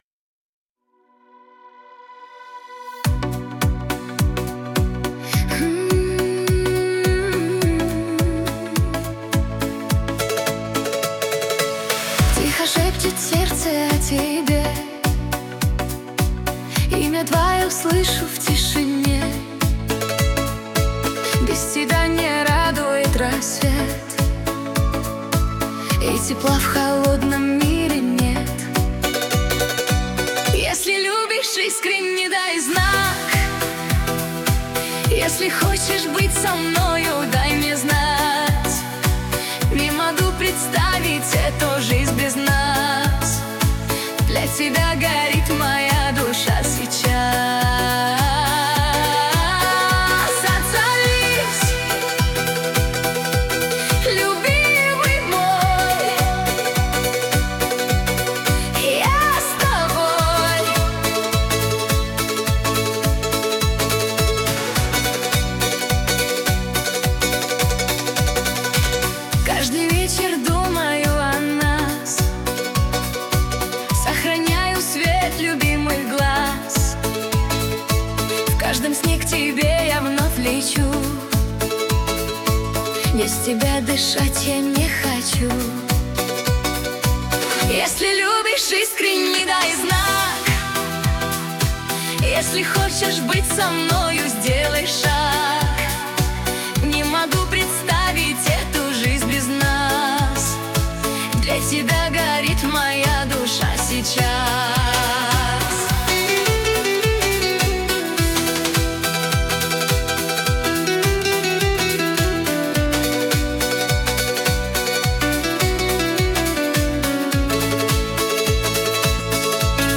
Трек создан с помощью нейросети